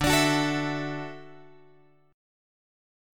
Dadd9 chord {x 5 4 7 5 x} chord